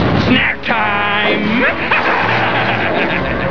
Carnage Sound Bytes!
From the Spider-Man animated series.